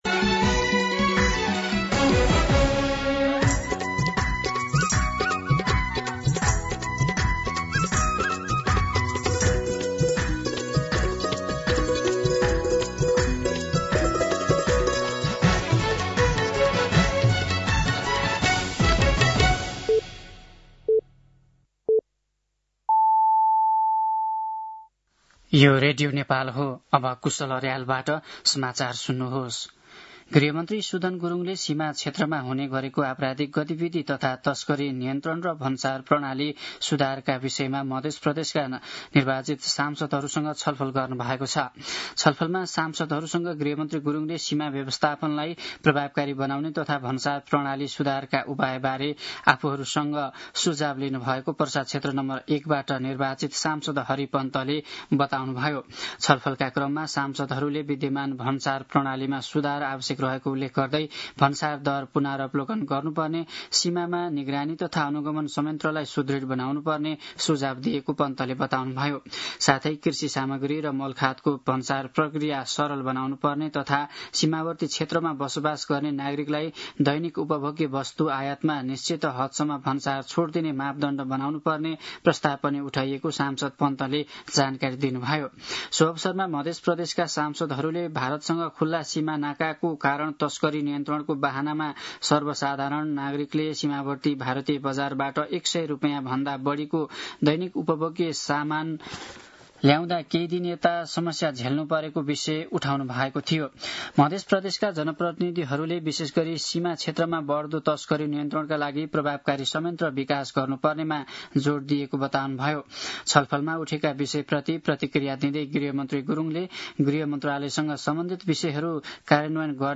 दिउँसो ४ बजेको नेपाली समाचार : ५ वैशाख , २०८३